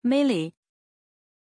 Pronunciation of Millie
pronunciation-millie-zh.mp3